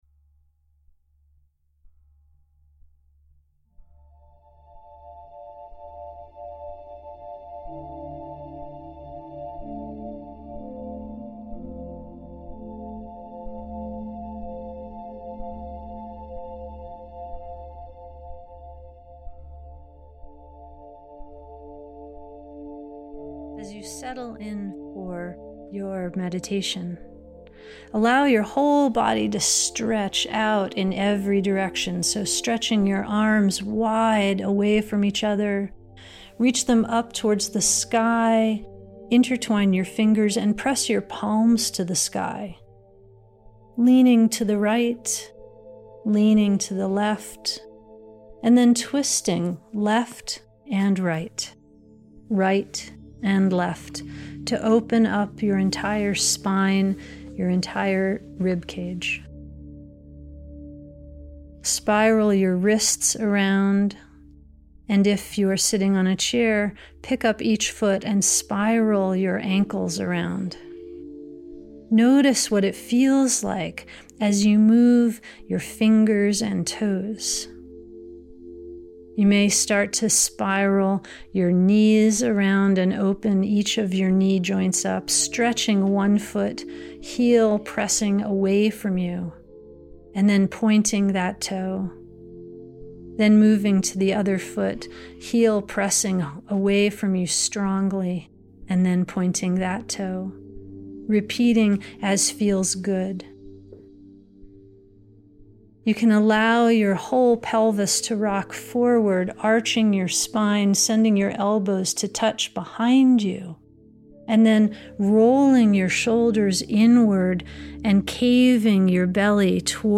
Guided Meditation: Depth of Your Heartbeat